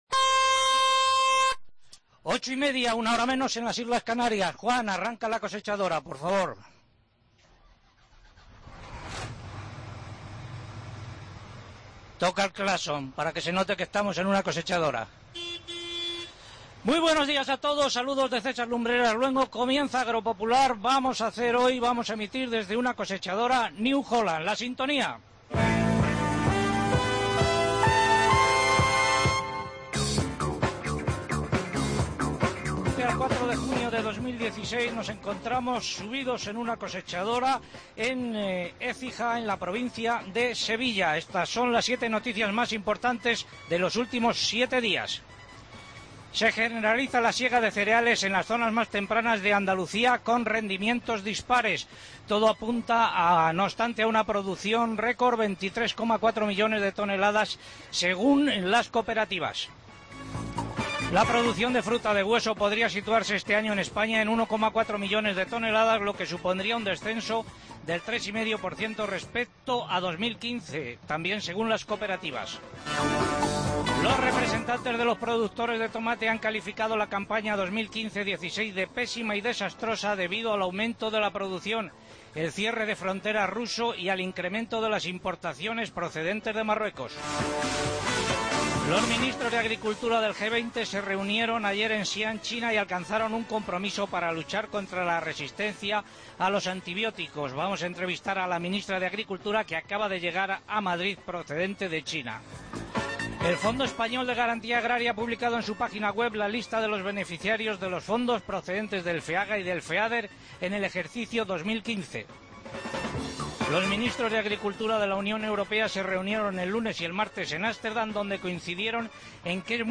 Agropopular desde una cosechadora